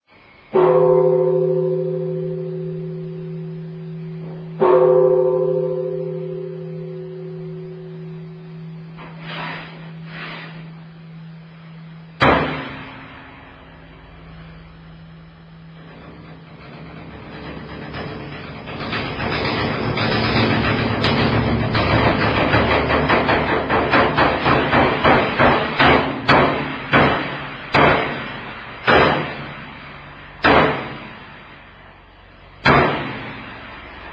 梵鐘から太鼓へと受け渡される場面です。
taiko.wma